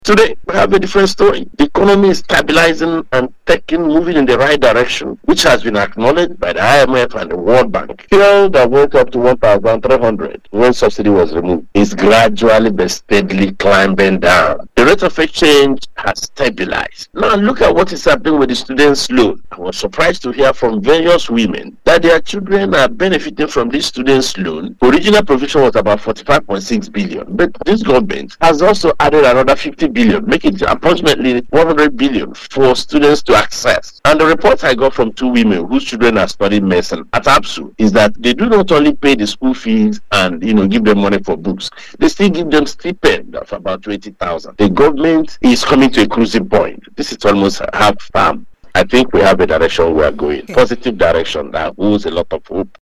This was made known by a Chieftain of the APC and the Member representing the South East on the board of the North East Commission Hon. Sam Onuigbo who was a guest on Family Love FM’s Open Parliament monitored by Dailytrailnews.